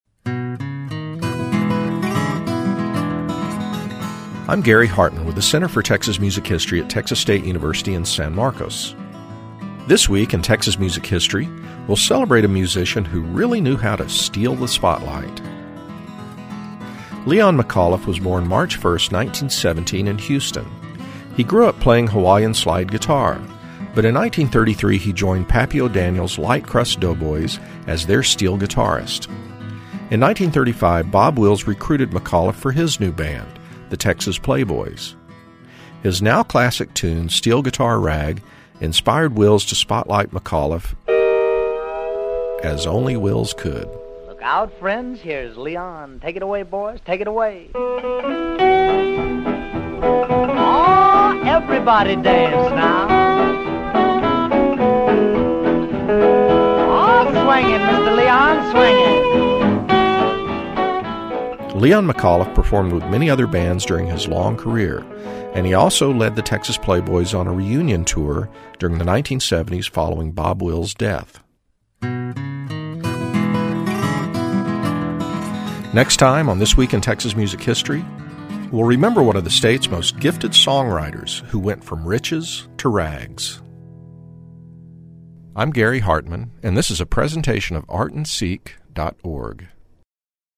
You can also hear This Week in Texas Music History on Friday on KXT and Saturday on KERA radio.